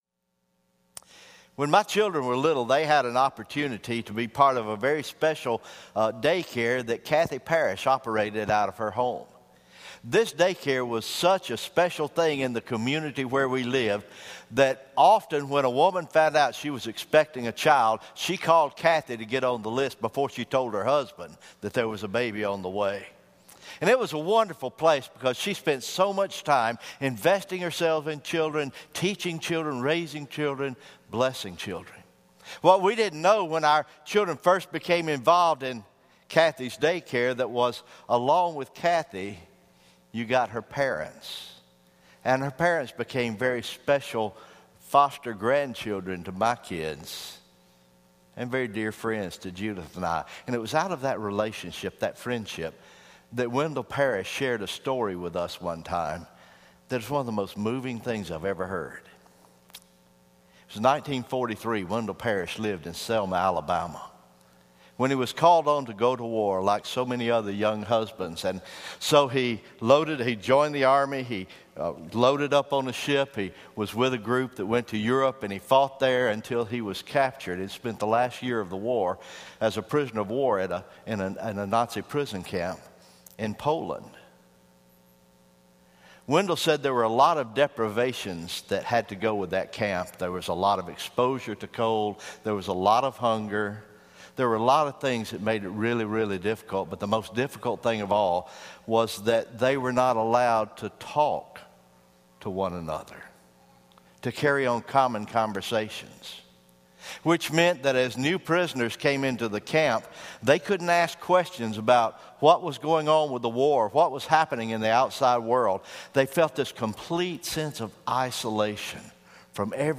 December 4, 2016 Morning Worship